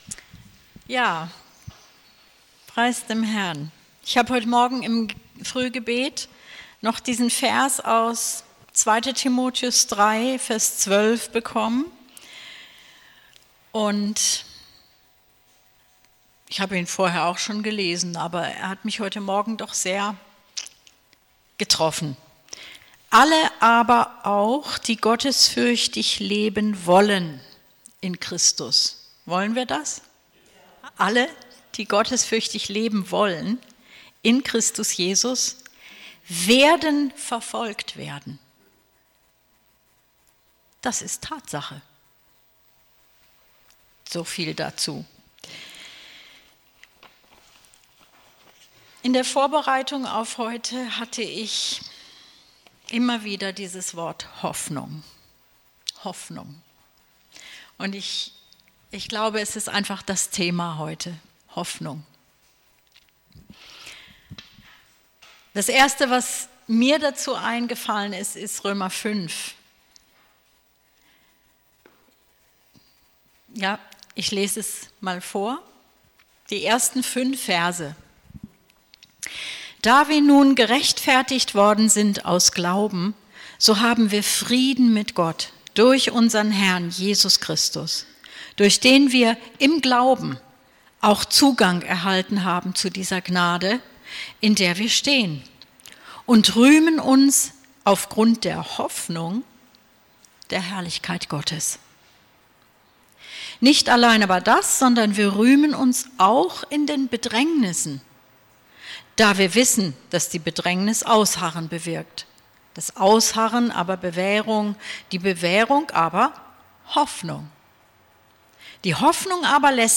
Die Hoffnung des Heils | Immanuel Gemeinde Herbolzheim